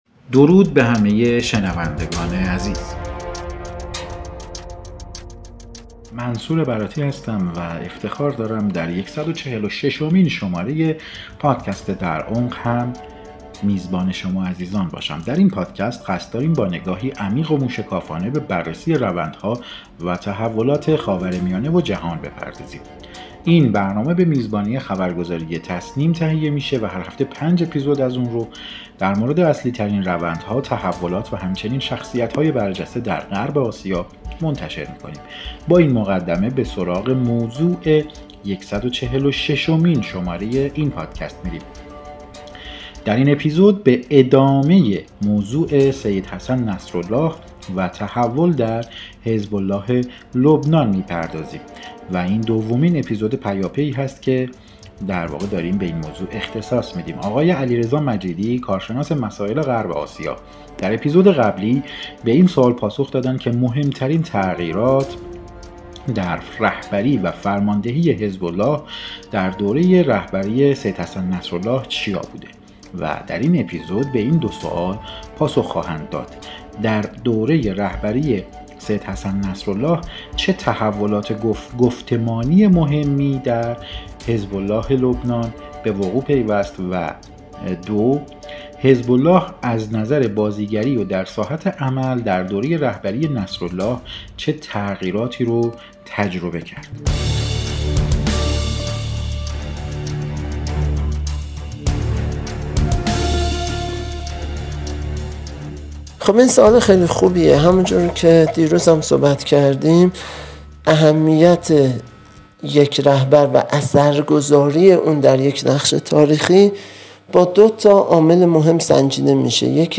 کارشناس مسائل غرب آسیا به سوال‌های در عمق درباره سید حسن نصرالله پاسخ می‌دهد.